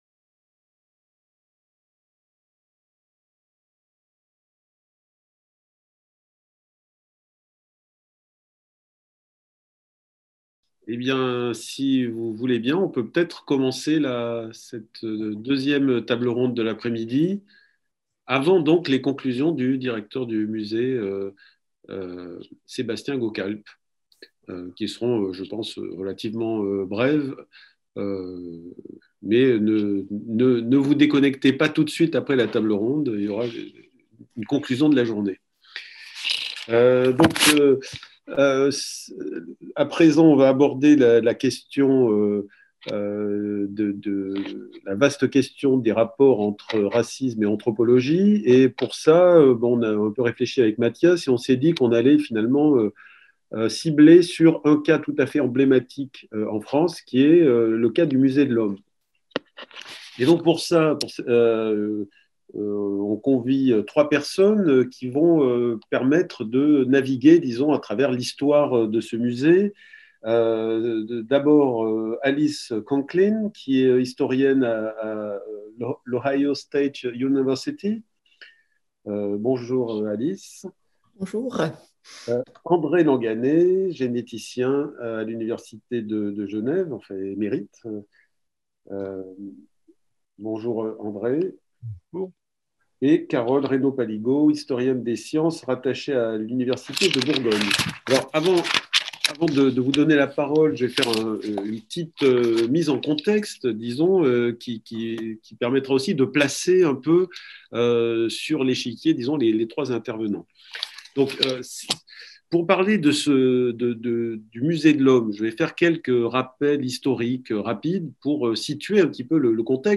Colloque | 6 mai